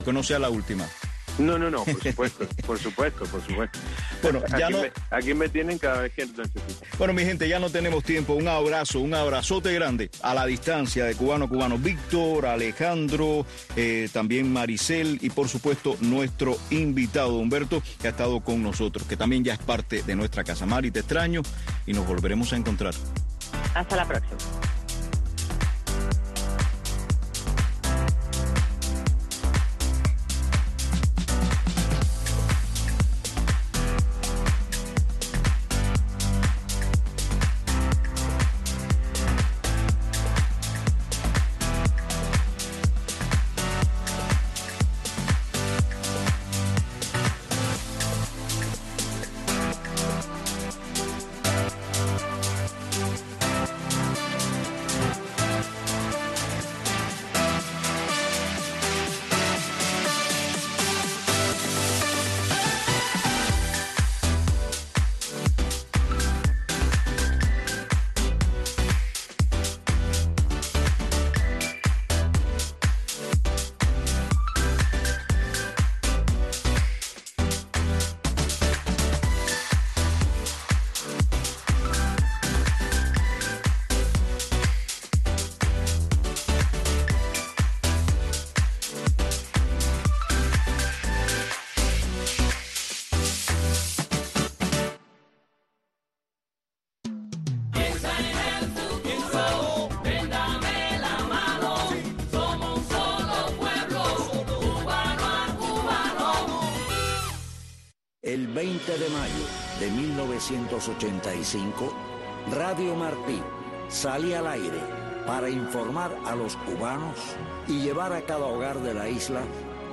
Café digital es un espacio radial que pretende ir más allá del solo objetivo de informar sobre nuevos avances de la ciencia y la tecnología.
Café digital traerá invitados que formen parte de la avanzada científica y tecnológica en el mundo y promoverá iniciativas e ideas que puedan llevar a cabo los jóvenes dentro de Cuba para dar solución a sus necesidades más cotidianas.